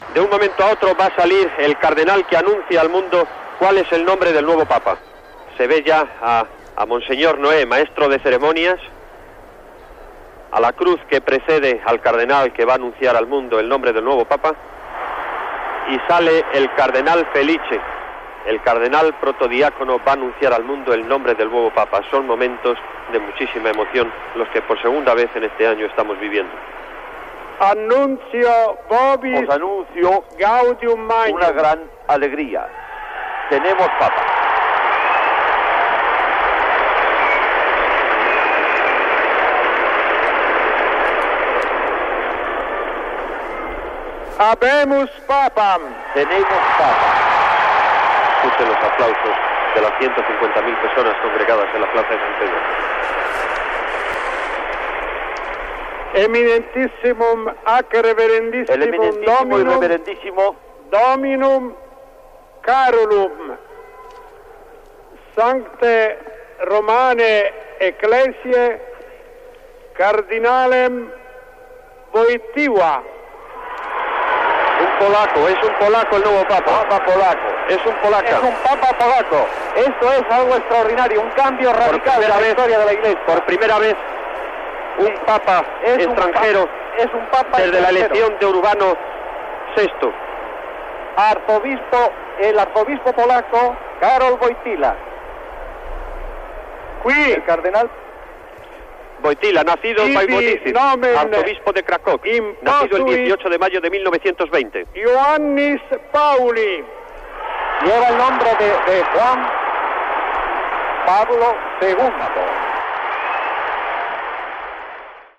Transmissió, des de la Plaça de Sant Pere de la Ciutat del Vaticà, de l'anunci que el cardenal Karol Józef Wojtyla ha estat escollit Sant Pare amb el nom de Joan Pau II.
Informatiu